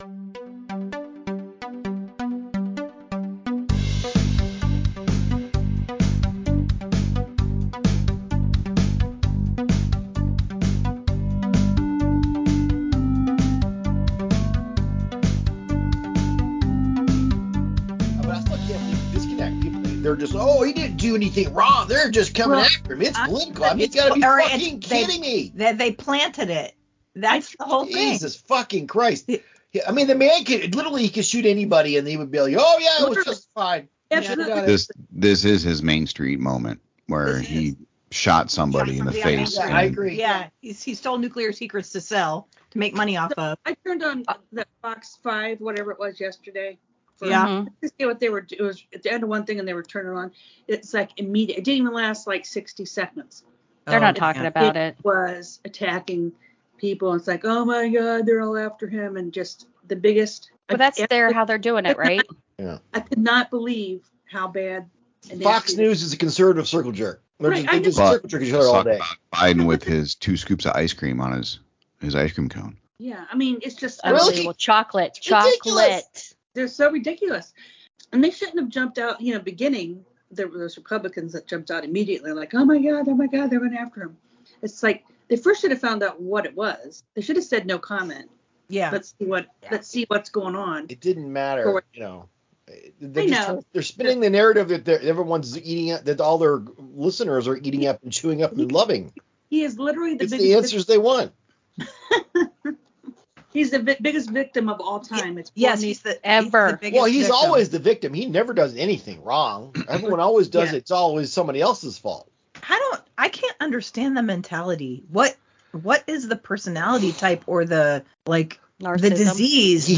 The gang is all here on this explosive roundtable full of discussion on: